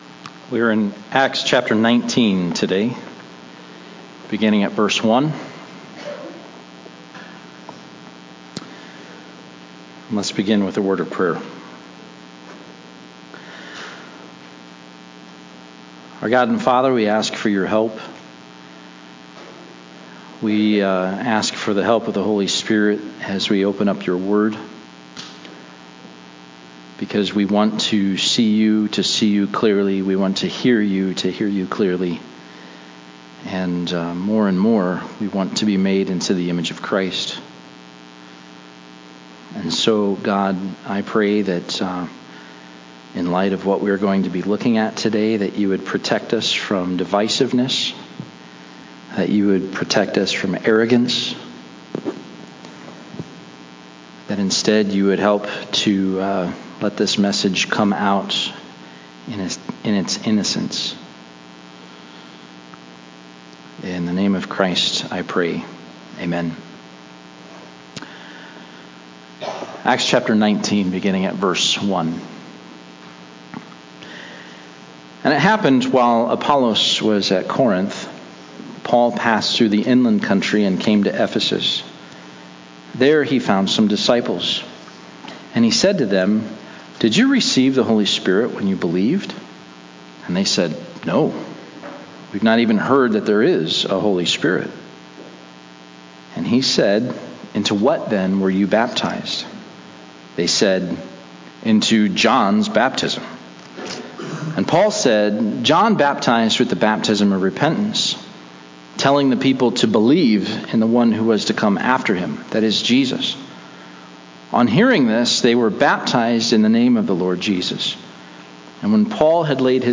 (A Portion of this recording was lost due to a power outage.)